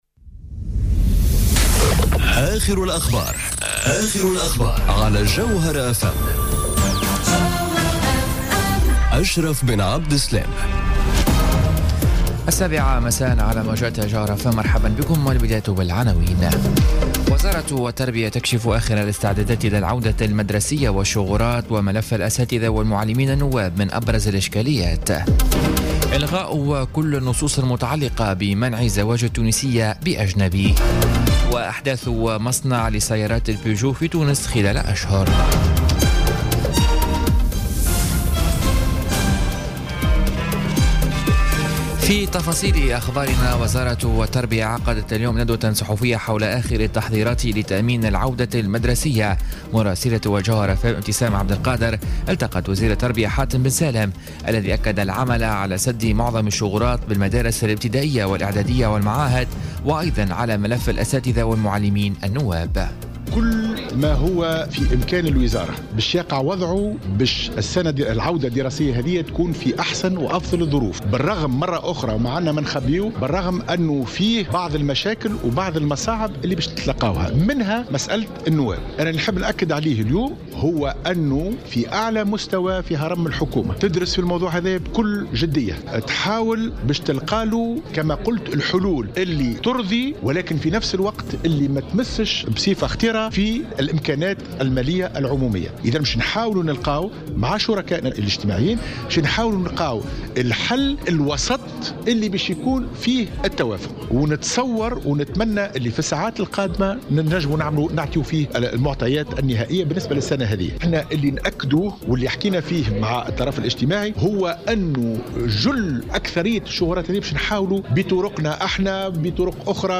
Journal Info 19h00 du Jeudi 14 Septembre 2017